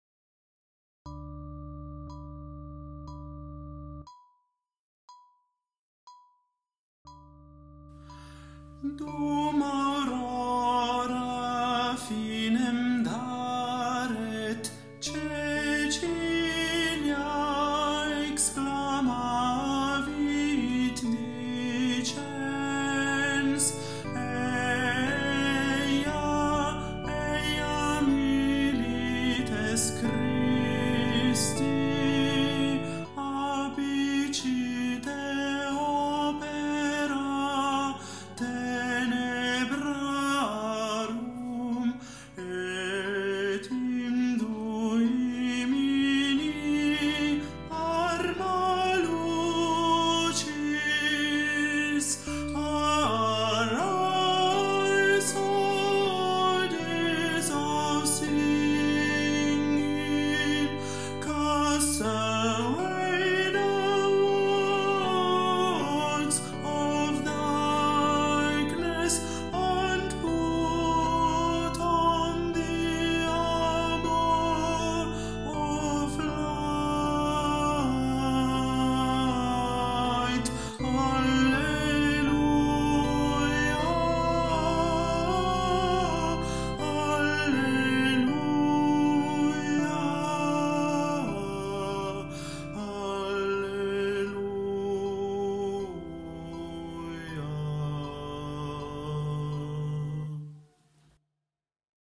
TENORE - Dum Aurora